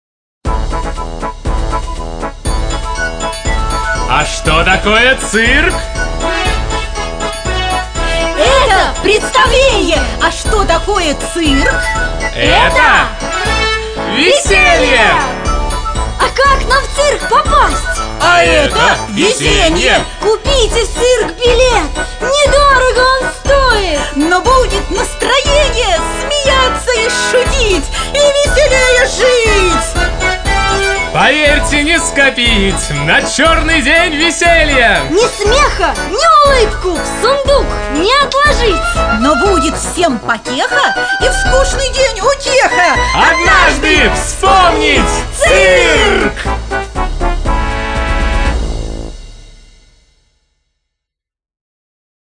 песенка из аудио сказки и мультфильфильма